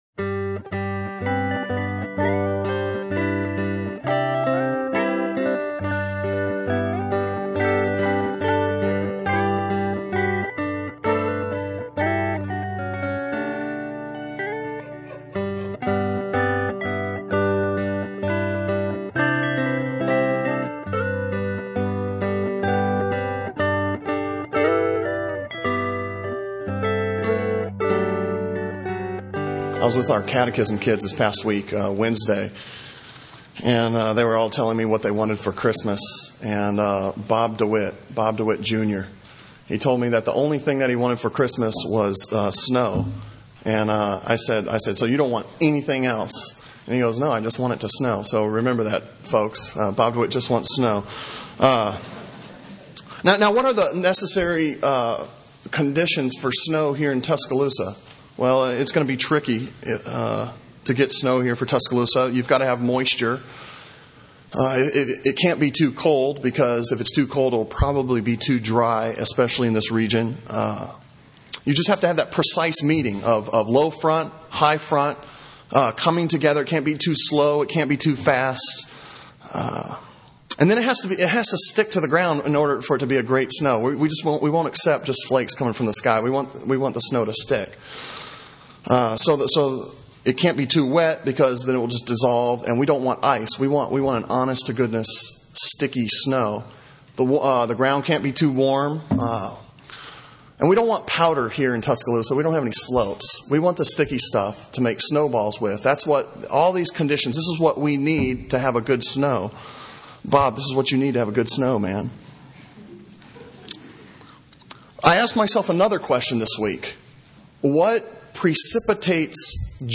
Sermon on Matthew 11:2-12 from December 16, 2007